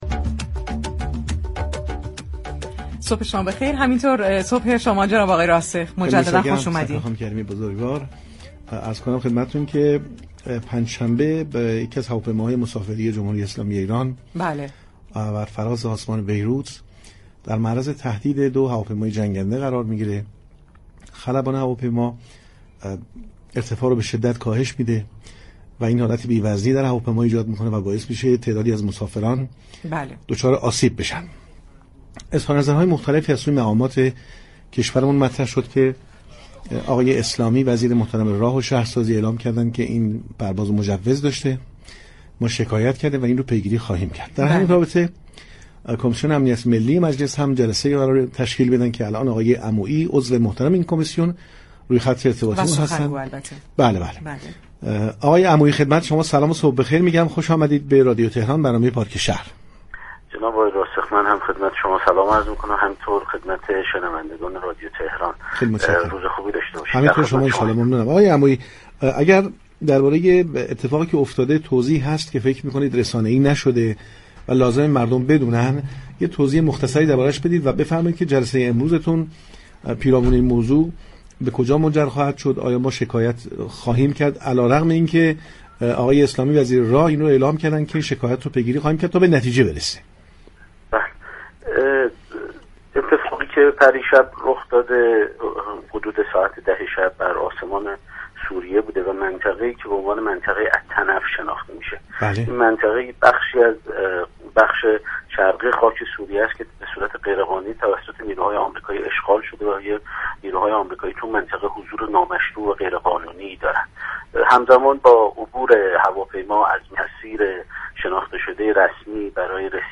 ابوالفضل عمویی، سخنگوی كمیسیون امنیت ملی مجلس شورای اسلامی در خصوص تهدید دو جت جنگی امریكایی علیه هواپیمای مسافربری ایران در آسمان سوریه در گفتگو با پارك شهر اظهار داشت: این اقدام امریكا تنها موجب افزایش خشم و نفرت مردم منطقه می‌شود.